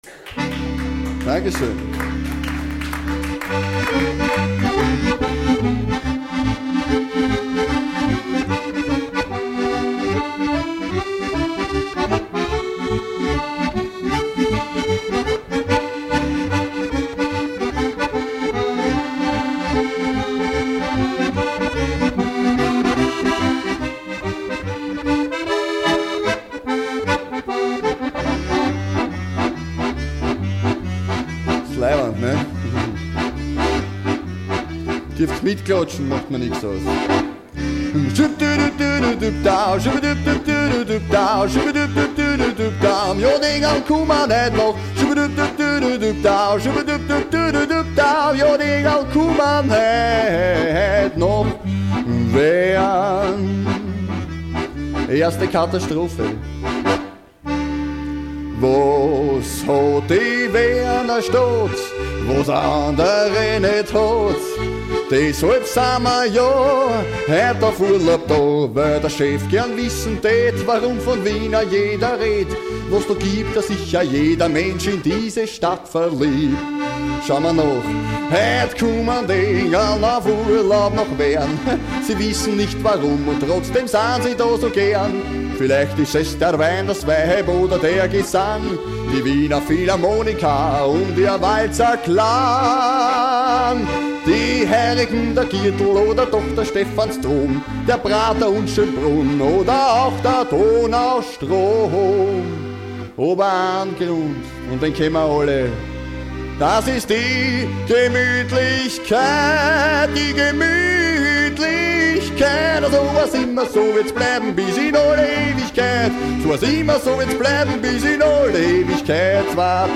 Kabarett Version